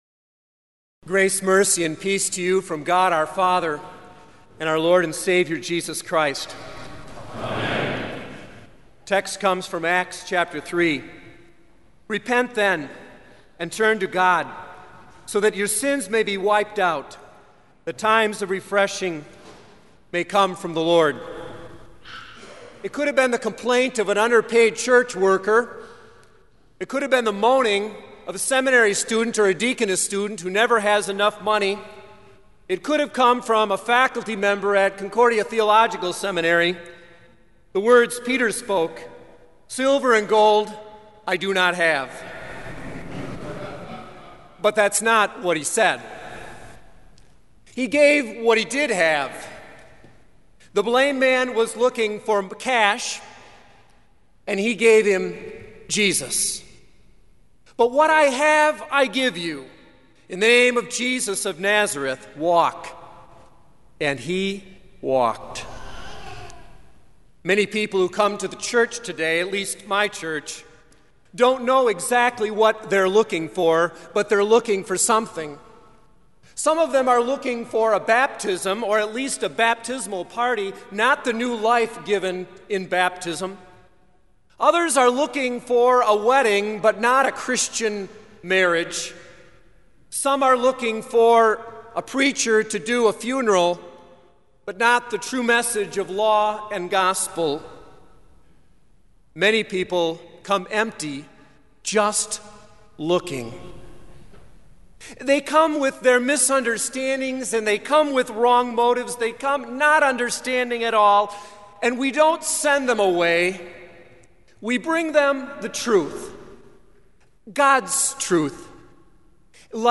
Kramer Chapel Sermon - May 02, 2006